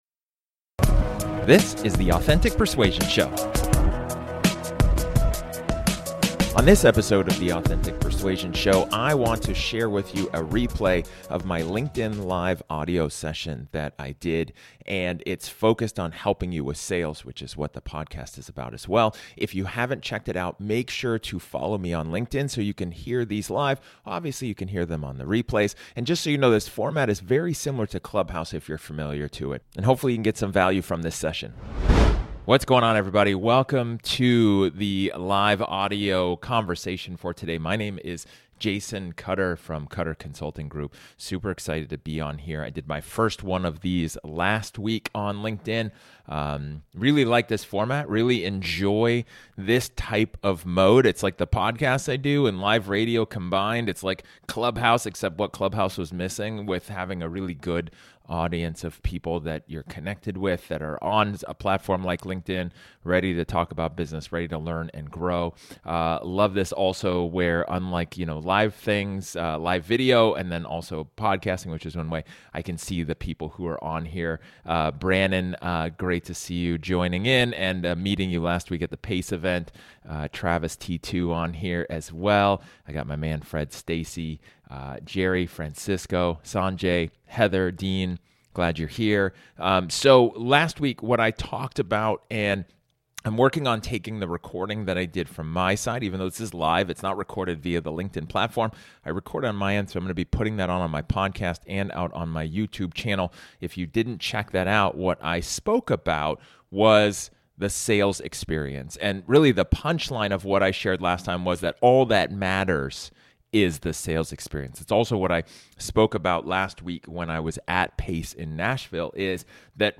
[543] LinkedIn Live Audio Replay: What Your Prospects Need From You